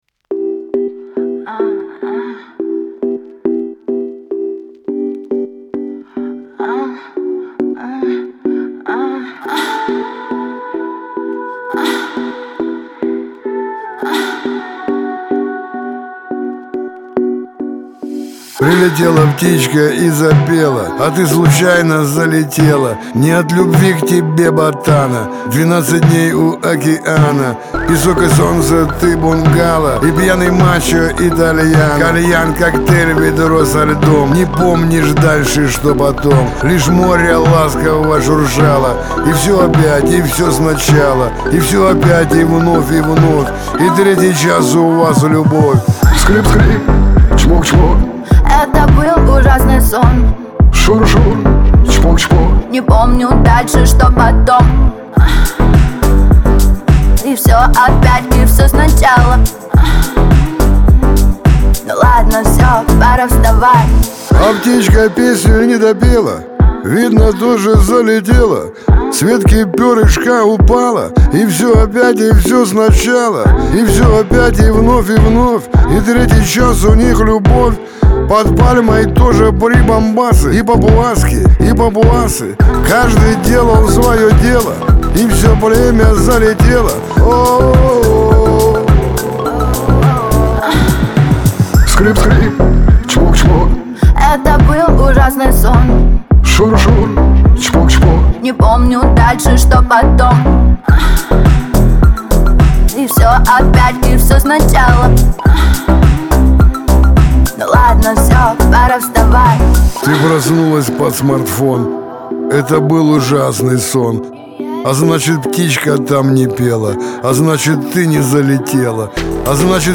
Лирика , Веселая музыка
Шансон